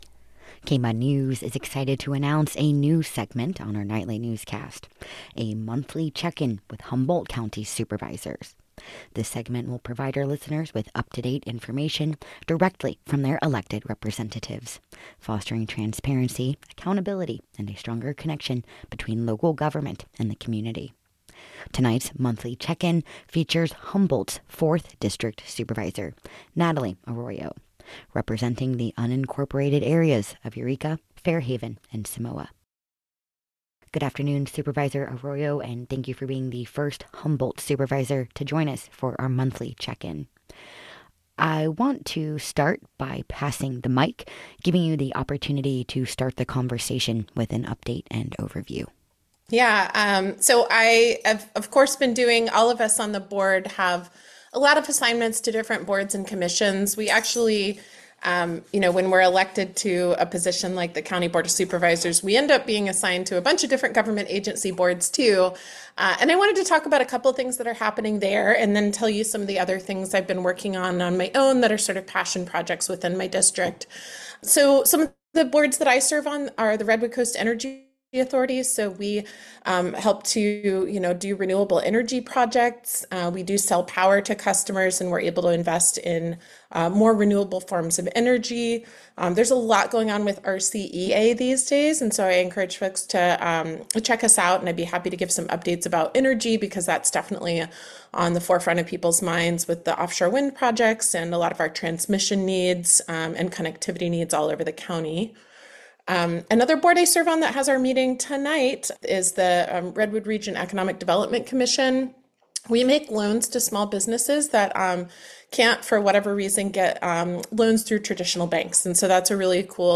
This segment will provide listeners with up-to-date information directly from their elected representatives, fostering transparency, accountability, and a stronger connection between local government and the community. Tonight’s Monthly Check-In features Humboldt’s 4th District Supervisor, Natalie Arroyo, who represents the unincorporated areas of Eureka, Fairhaven, and Samoa. Arroyo is actively addressing a range of critical issues affecting her district, from advancing renewable energy and supporting economic development to tackling healthcare access and fiscal challenges.